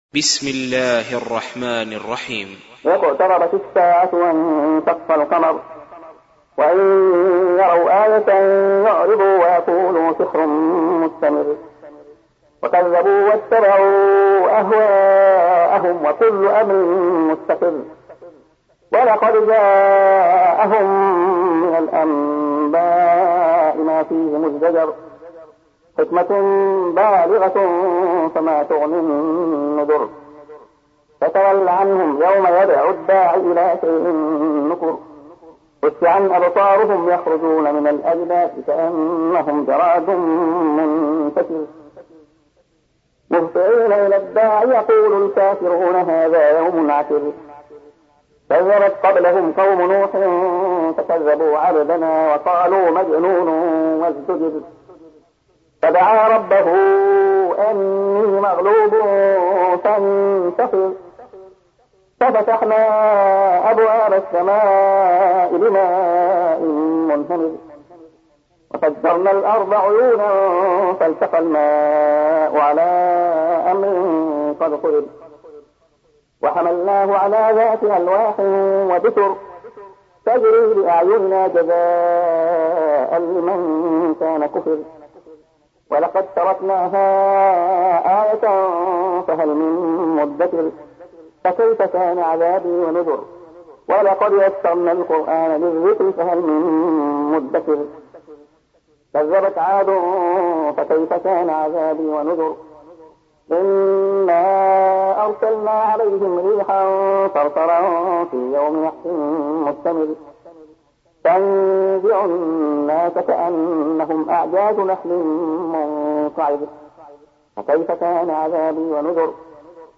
سُورَةُ القَمَرِ بصوت الشيخ عبدالله الخياط